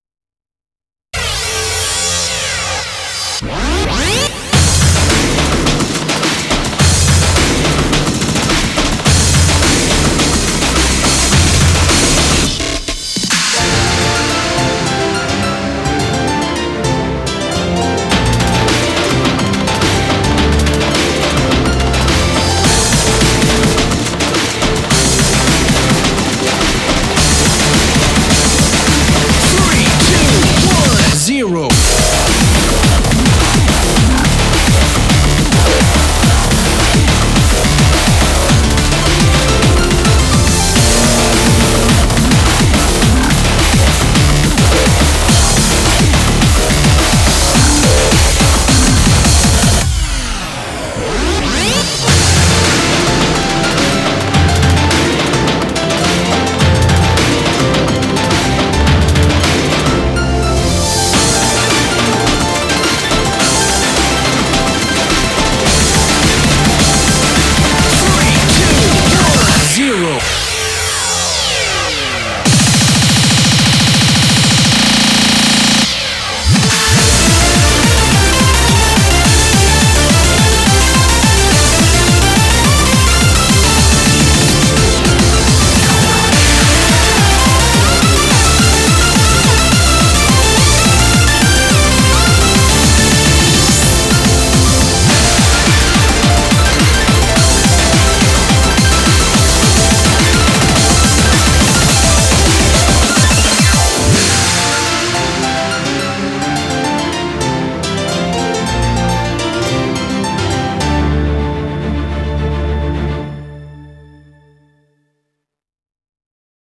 BPM106-848
Be careful of the speedup from 106 to 212→424848 BPM!